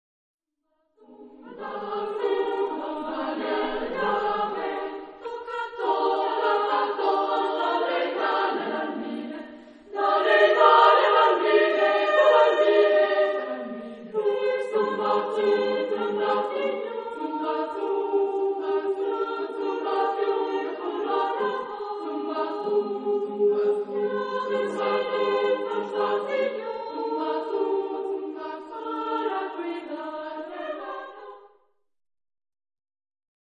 Genre-Style-Form: Christmas carol
Mood of the piece: joyous ; lively
Type of Choir: SMAA  (4 children OR women voices )
Tonality: G major